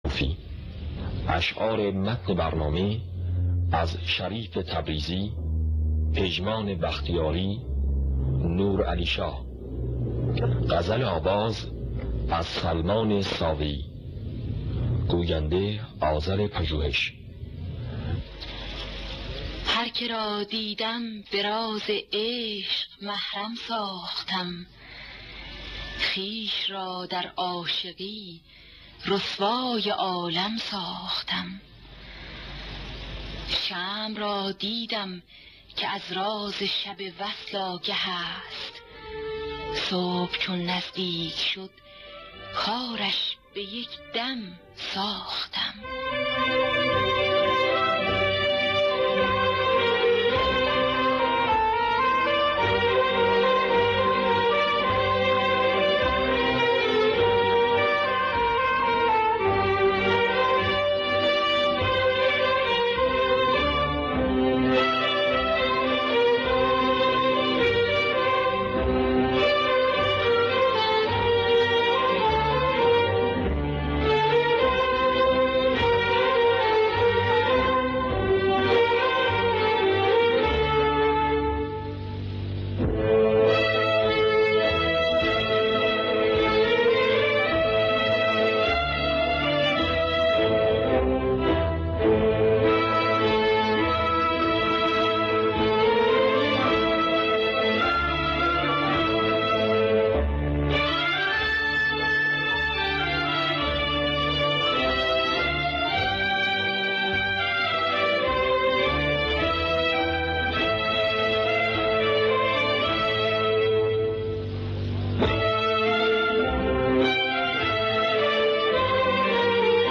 در دستگاه ابوعطا